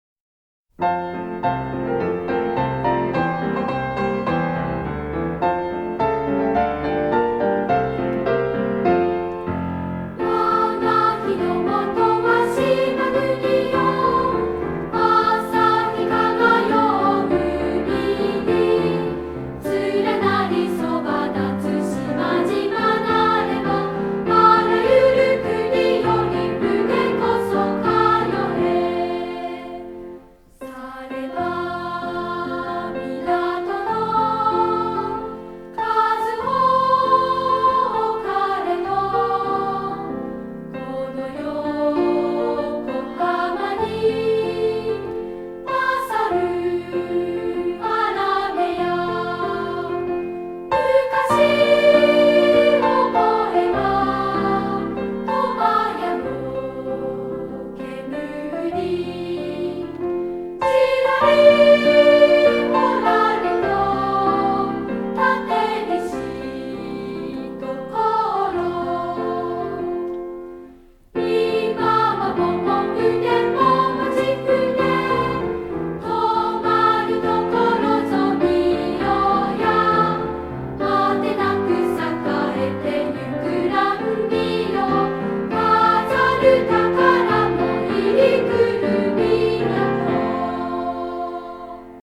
少年少女合唱団の歌が、昔聞いた歌に近い。